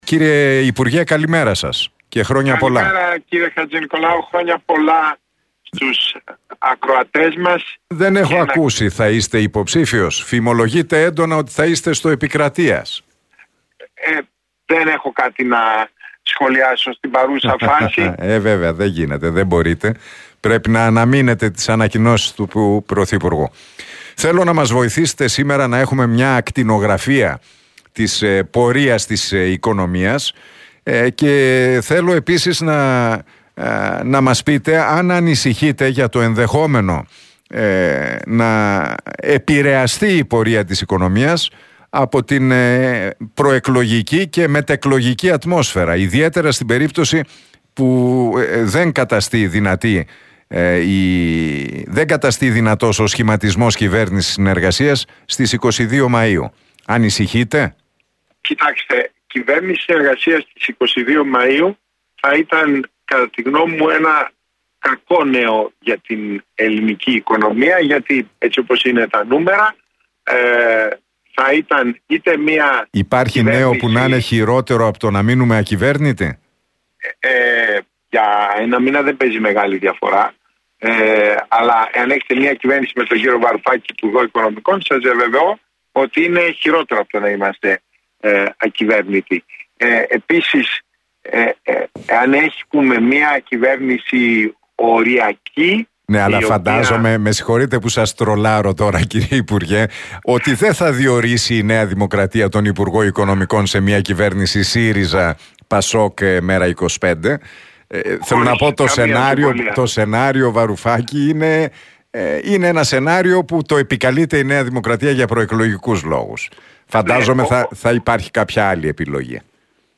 Αν έχετε μια κυβέρνηση με υπουργό Οικονομικών τον κ. Βαρουφάκη, σας διαβεβαιώ ότι είναι χειρότερο από το να είμαστε ακυβέρνητοι» δήλωσε ο Θεόδωρος Σκυλακάκης μιλώντας στον Realfm 97,8 και στον Νίκο Χατζηνικολάου.